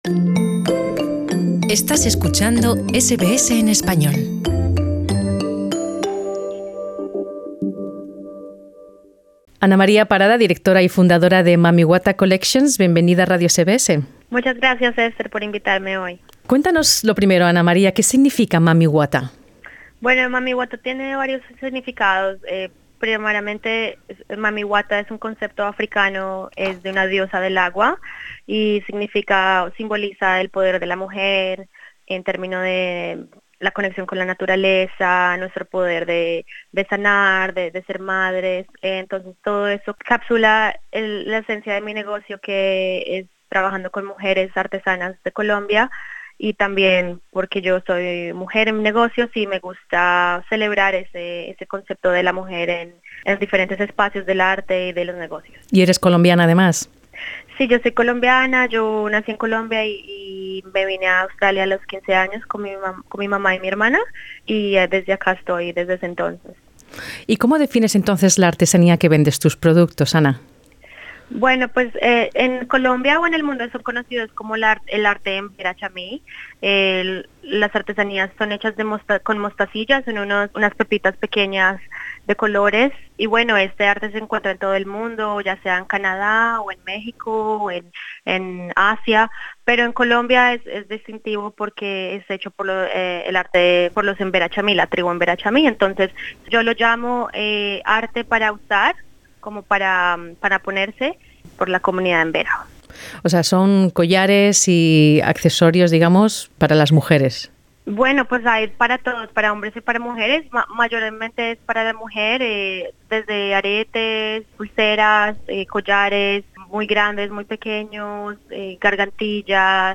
Escucha aquí la entrevista con su fundadora.